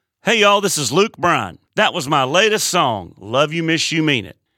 LINER Luke Bryan (LYMYMI) 6